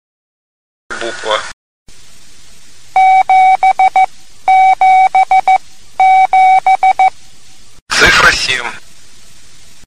Предлагаю для обучения приема использовать еще большее приближение к нашим занятиям - сперва звучит слово "Буква", затем три раза повторяется морзе и в это время называем букву (или цифру), затем слушаем правильный ответ.